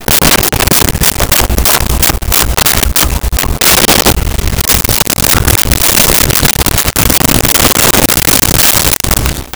Ape Grunts Breaths
Ape Grunts Breaths.wav